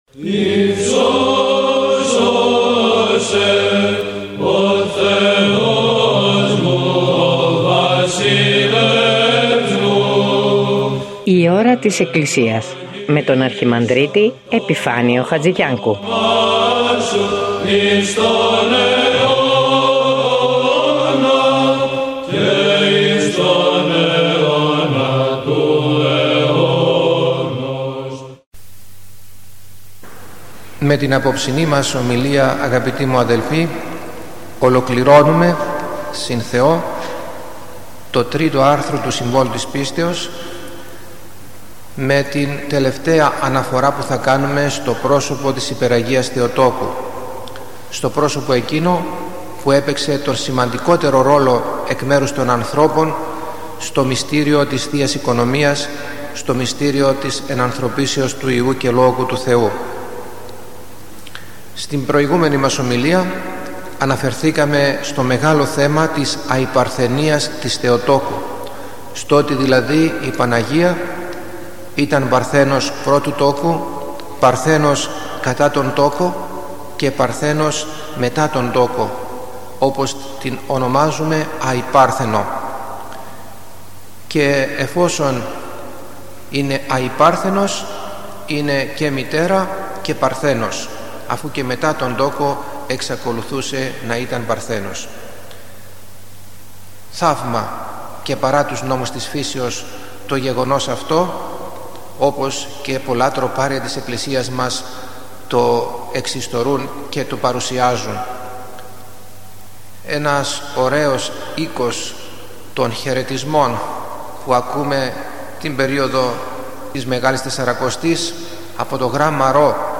Μια ένθετη εκπομπή που μεταδίδονται ομιλίες